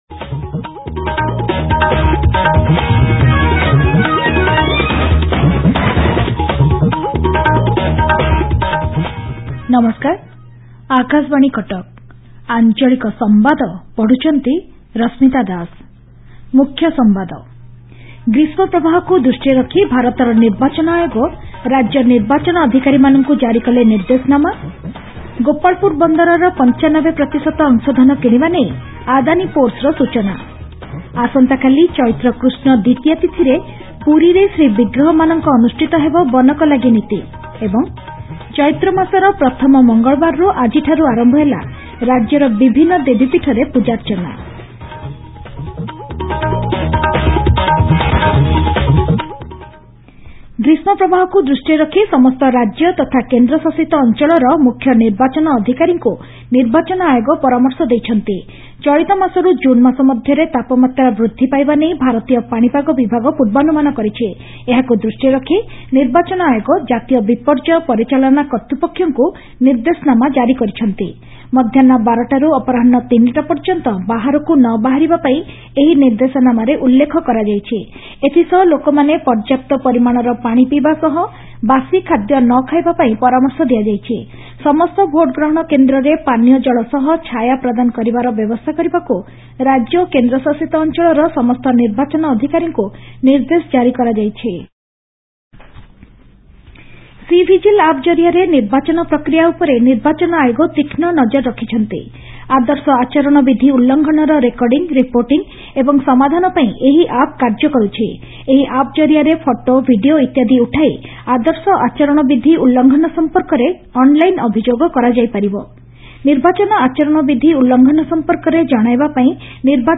Transcript summary Play Audio Evening News
cuttack-eve.mp3